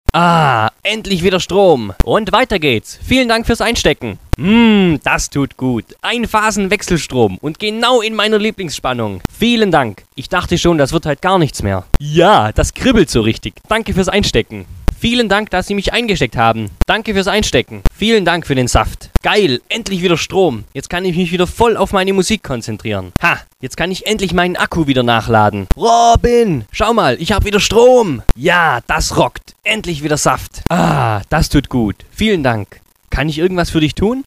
Kategorie 2: Sprüche, die beim Einstecken des Baums abgespielt werden: